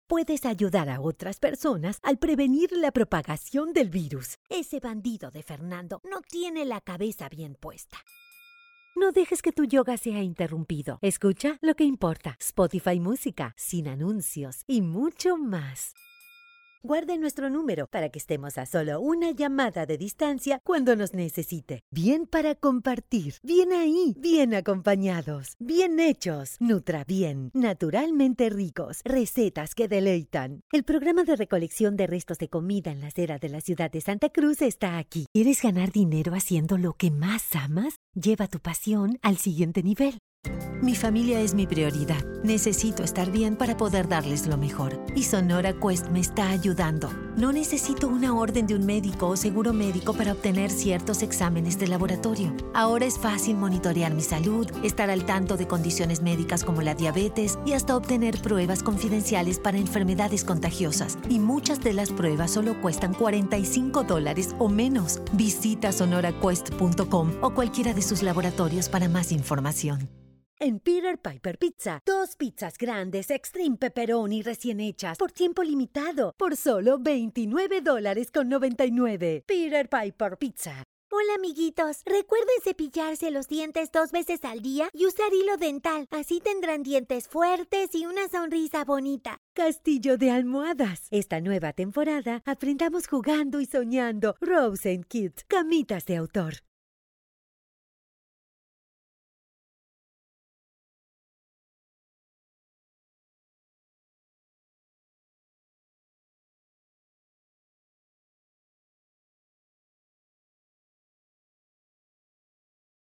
Female
Adult (30-50), Older Sound (50+)
Radio Commercials
Voz comercial persuasiva en español neutro latinoamericano que impulsa conversión. Versatilidad comprobada: desde marcas premium con tono sofisticado hasta productos masivos con cercanía auténtica.